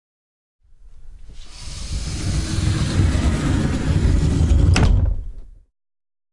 衣柜推拉门 " 衣柜推拉门关闭缓慢的金属 004
Tag: 滑动 打开 关闭 衣柜 金属 家庭 弗利